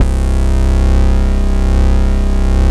13 MOOG BASS.wav